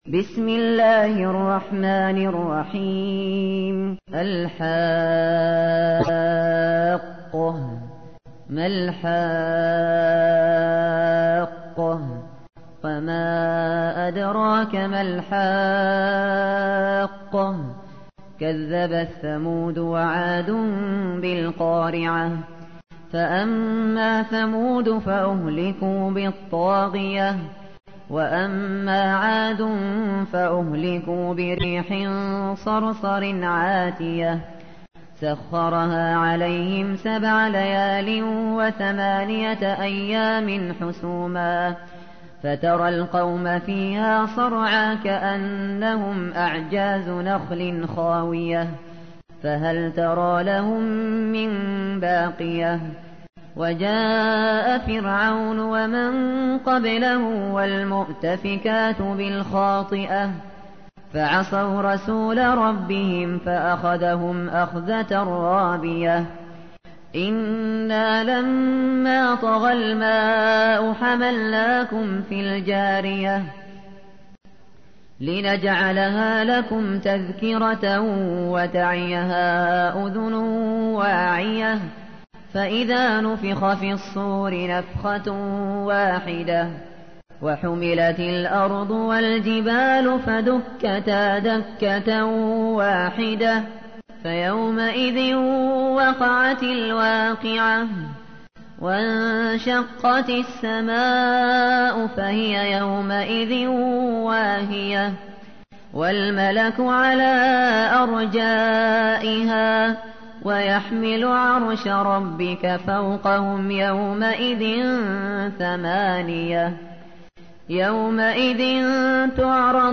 تحميل : 69. سورة الحاقة / القارئ الشاطري / القرآن الكريم / موقع يا حسين